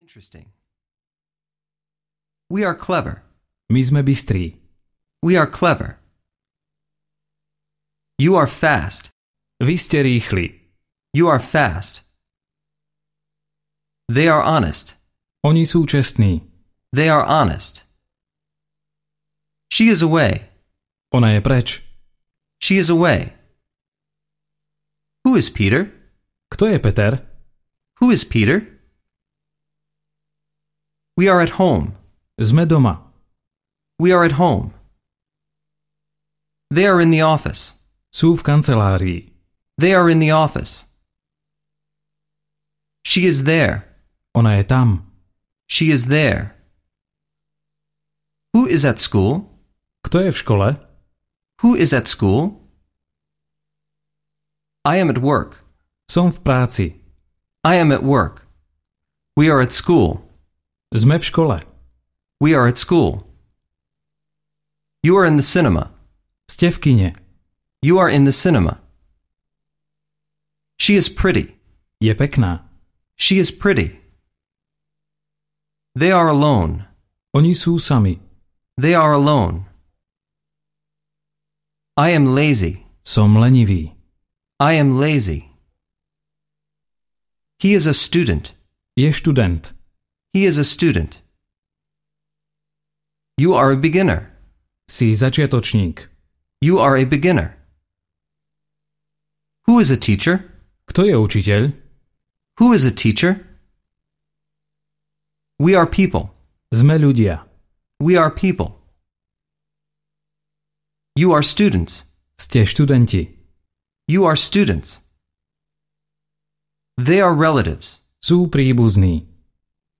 Ukázka z knihy
Každú vetu počujete najprv po anglicky, potom v slovenskom preklade a znovu v originálnom znení.